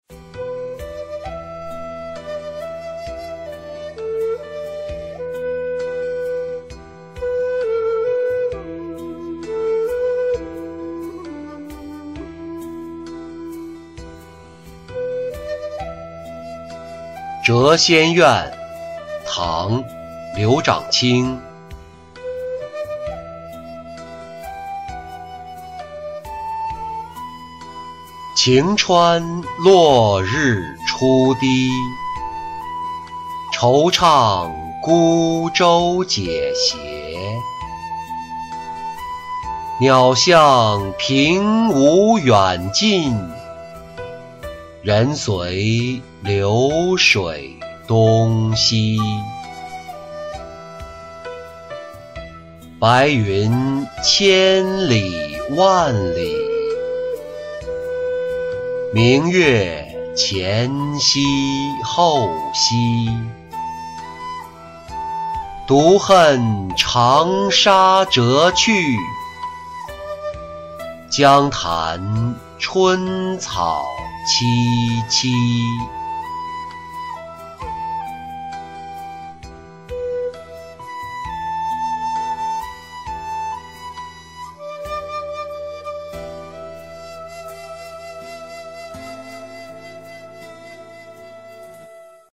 谪仙怨·晴川落日初低-音频朗读